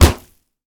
punch_grit_wet_impact_06.ogg